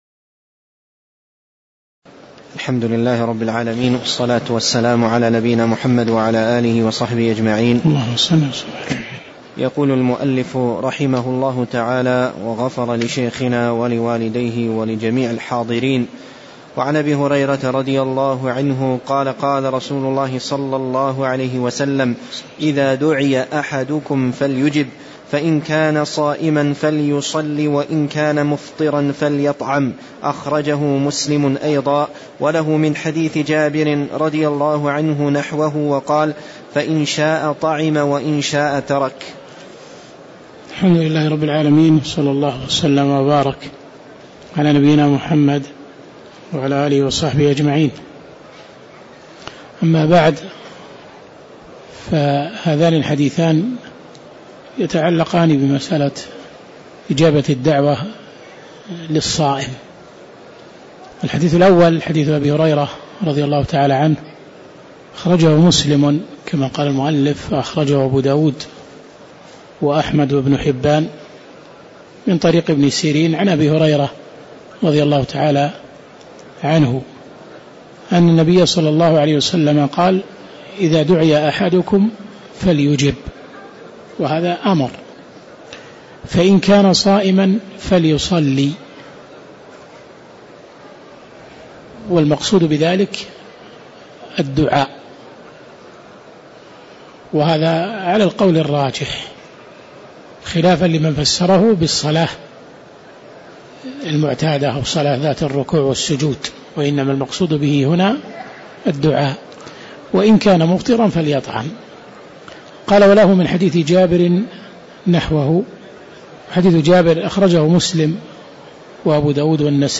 تاريخ النشر ١٩ رجب ١٤٣٧ هـ المكان: المسجد النبوي الشيخ